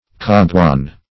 kaguan - definition of kaguan - synonyms, pronunciation, spelling from Free Dictionary Search Result for " kaguan" : The Collaborative International Dictionary of English v.0.48: Kaguan \Ka`gu*an"\, n. (Zool.) The colugo.